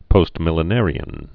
(pōstmĭl-ə-nârē-ən)